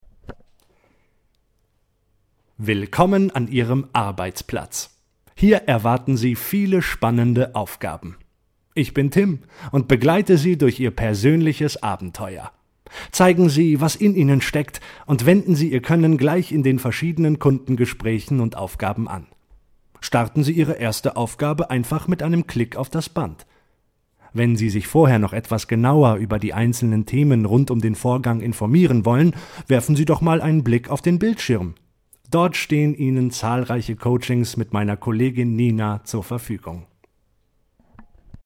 Frische, dynamische, flexible und markante Sprech- und Gesangsstimme, optimal für Werbung, Voice Over, Synchron, Dokumentationen, Hörbücher und Hörspiele.
Sprechprobe: eLearning (Muttersprache):
My voice is fresh, dynamic, warm, flexible and striking.